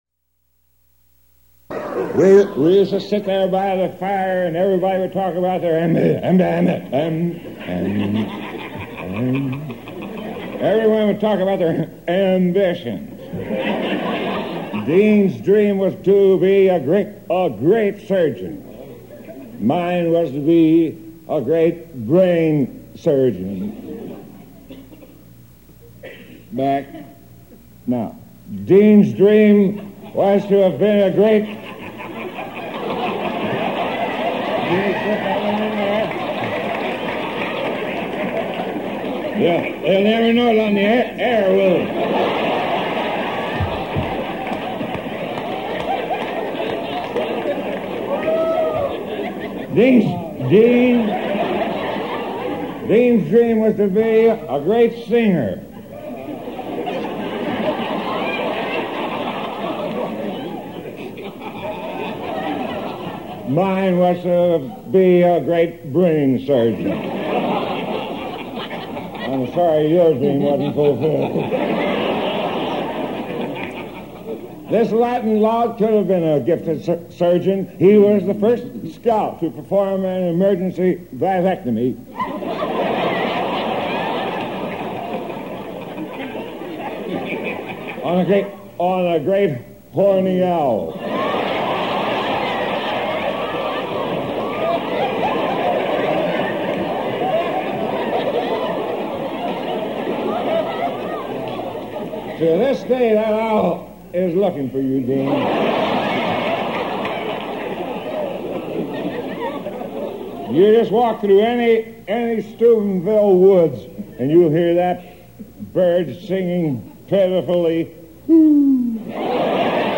Tags: Comedy